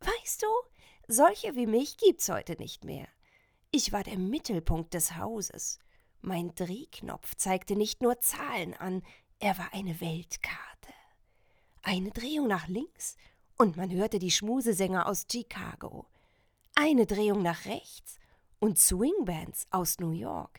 sehr variabel, hell, fein, zart, markant
Mittel minus (25-45)
Tale
Audio Drama (Hörspiel), Audiobook (Hörbuch), Game, Tale (Erzählung)